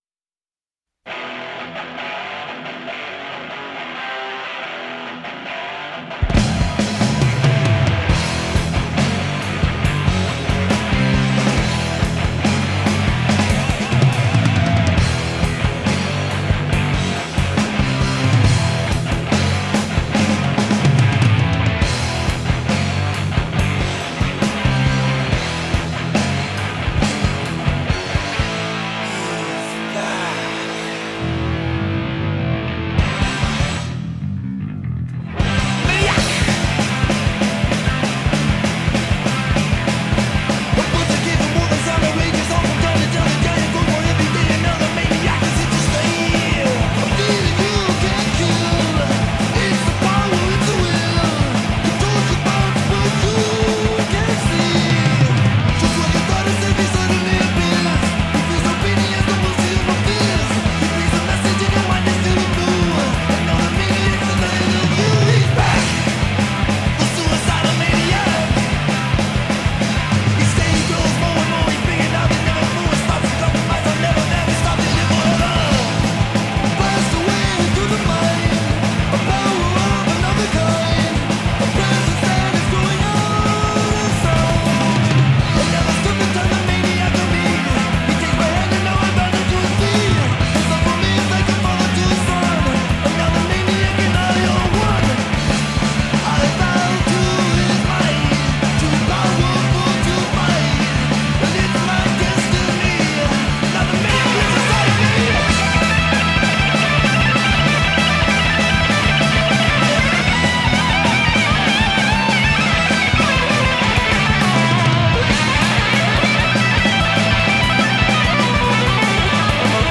Crossover Thrash, Hardcore Punk, Thrash Metal